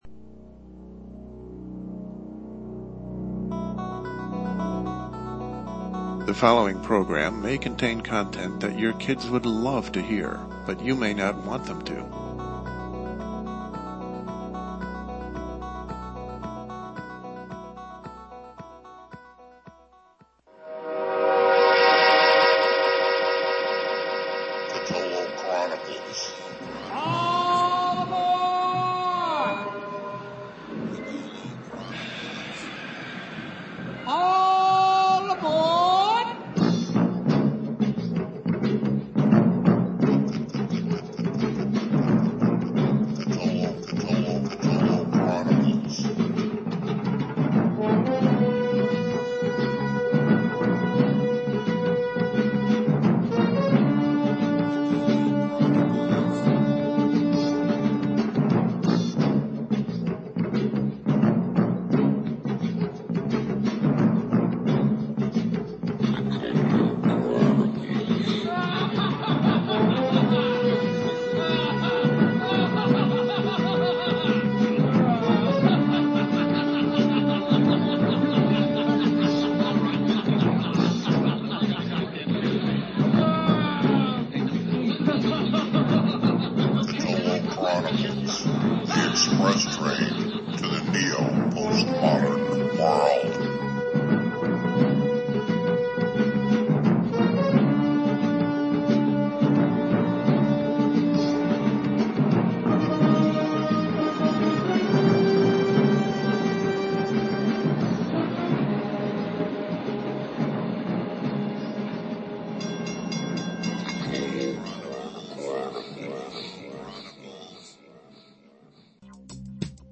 LIVE, Thursday, July 19 at 9 p.m. Let’s try this again—time to hit the lists, this time one of recommended humor books—recommended by sources other than our host. Check out the 90 books said to be “must-reads” by anyone who loves comedy, according to the source used in a major publication.